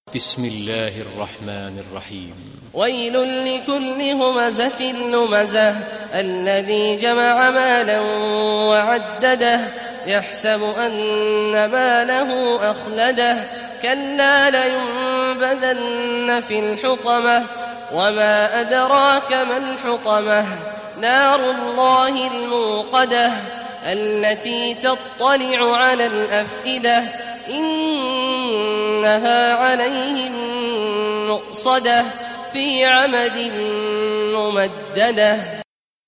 Surah Al-Humazah Recitation by Sheikh Awad Juhany
Surah Al-Humazah, listen or play online mp3 tilawat / recitation in Arabic in the beautiful voice of Sheikh Abdullah Awad al Juhany. Download audio tilawat of Surah Al-Humazah free mp3 in best audio quality.